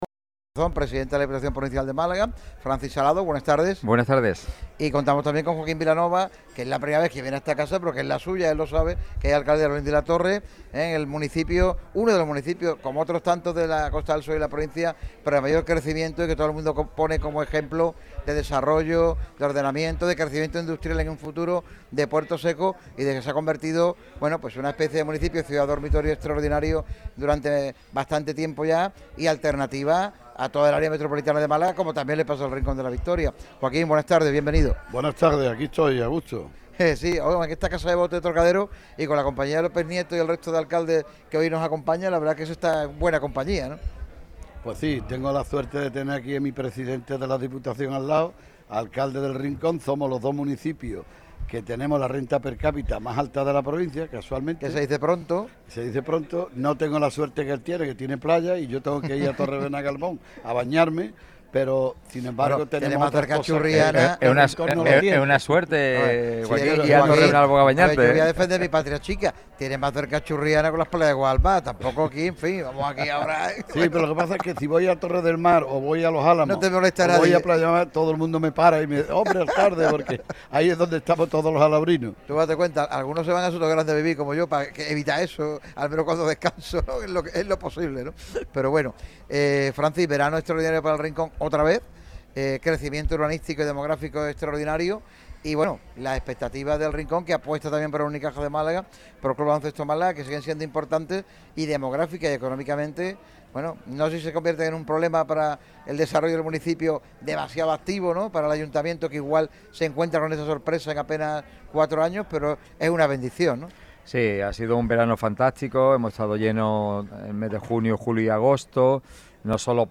El actual presidente de la Diputación de Málaga y alcalde del Rincón de la Victoria ha acudido y colaborado en el programa matinal de Radio MARCA Málaga realizado en el restaurante Trocadero Casa de Botes, ubicado en el Paseo de la Farola, 25. Salado no ha dudado en mojarse sobre el tratamiento del Gobierno a eventos como la Solheim Cup.